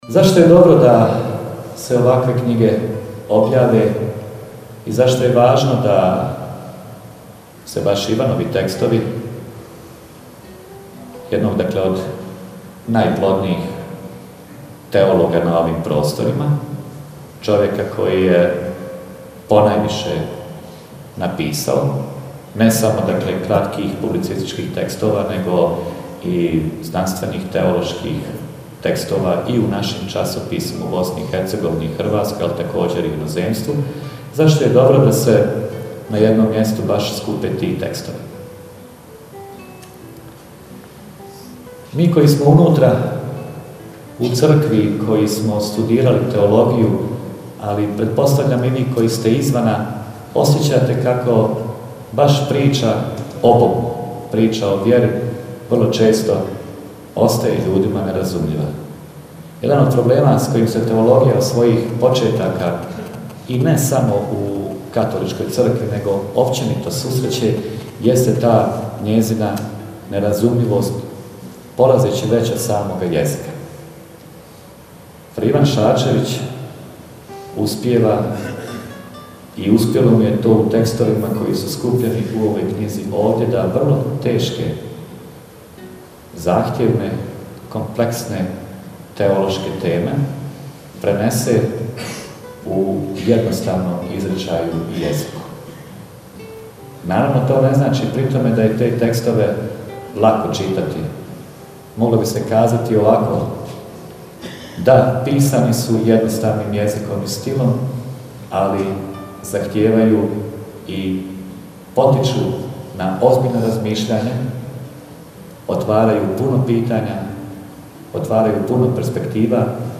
U Domu kulture u Prozoru
Poslušajte predstavljanje: